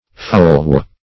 folwe - definition of folwe - synonyms, pronunciation, spelling from Free Dictionary Search Result for " folwe" : The Collaborative International Dictionary of English v.0.48: Folwe \Fol"we\, v. t. To follow.